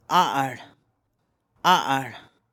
Stress falls on the initial syllable of the root word.
Like the pause in ‘uh-oh’